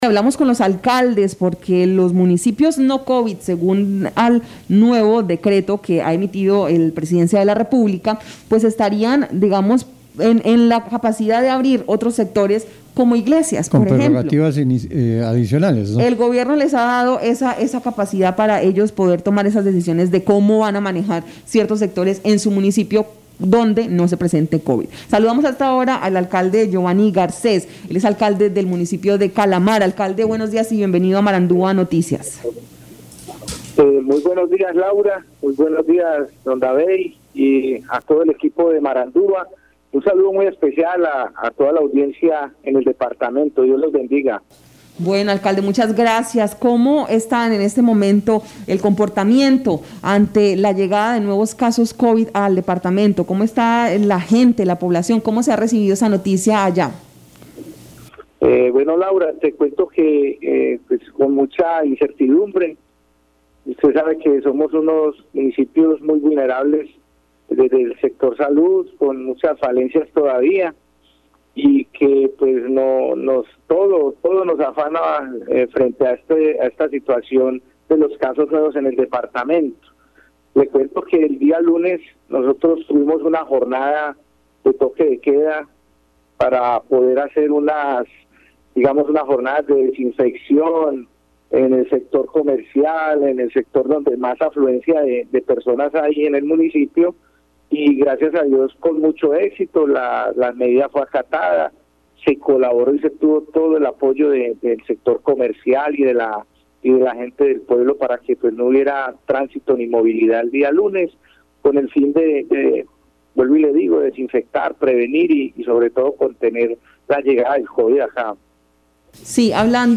Escuche a Giovanny Garcés, alcalde de Calamar, Guaviare.